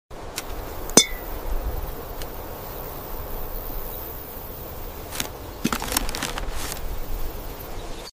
ASMR glass garden vegetables, cabbage